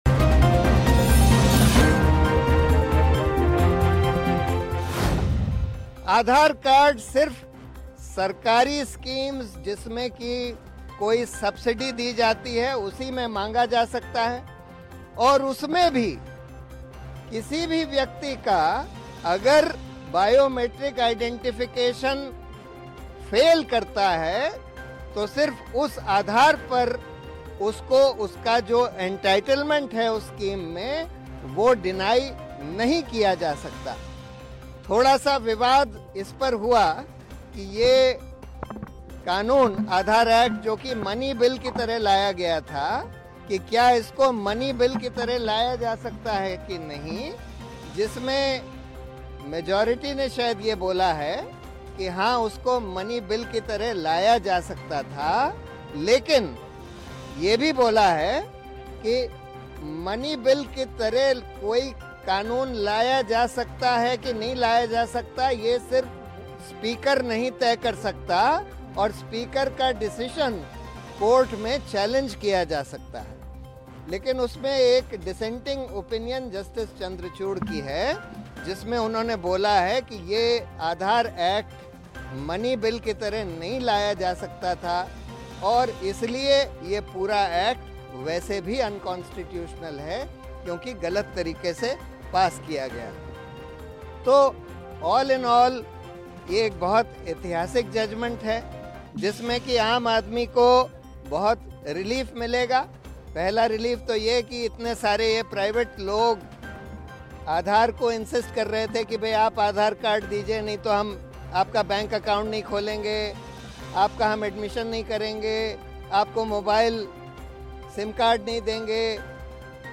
न्यूज़ रिपोर्ट - News Report Hindi / सरकारी सुविधाओं का फायदा लेने में आधार अहम